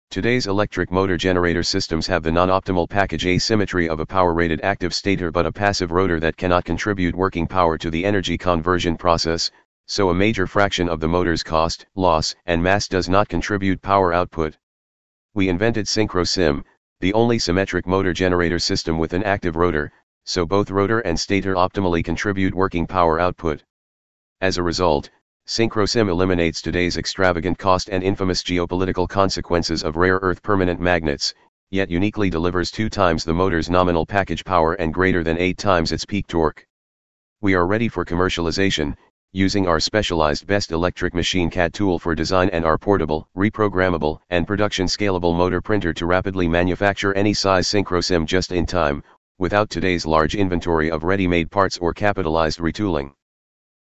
ELEVATOR SPEECH